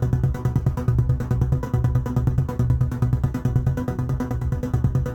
Index of /musicradar/dystopian-drone-samples/Tempo Loops/140bpm
DD_TempoDroneC_140-B.wav